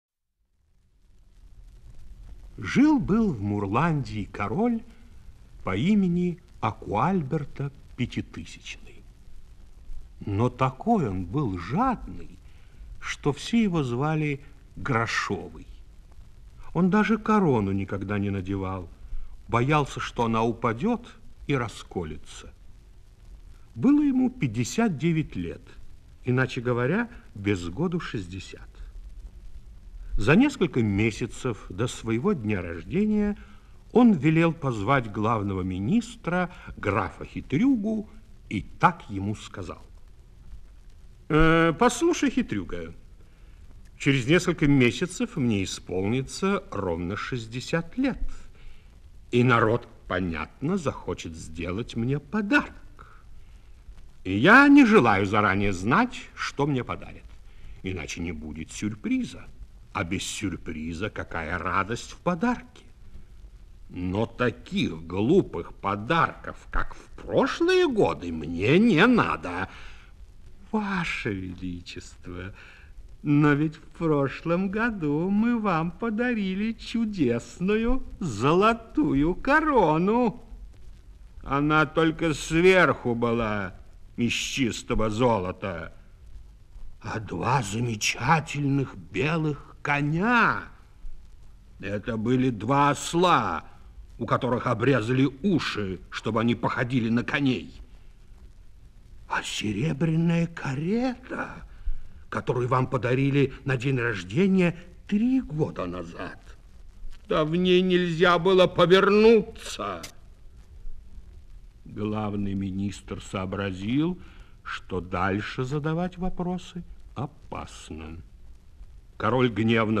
Жареная кукуруза - аудиосказка Джанни Родари - слушать онлайн